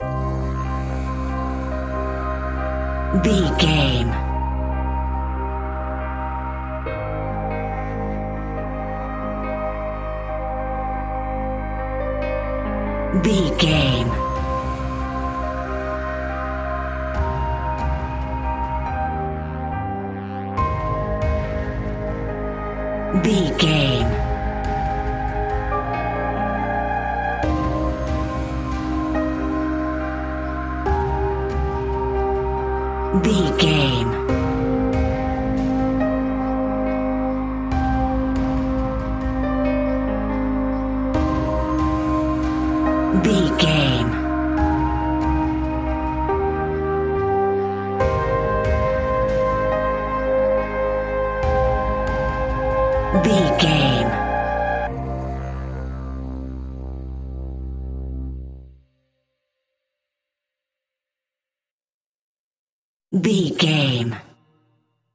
In-crescendo
Thriller
Aeolian/Minor
ominous
dark
haunting
eerie
piano
strings
synthesiser
percussion
brass
horror music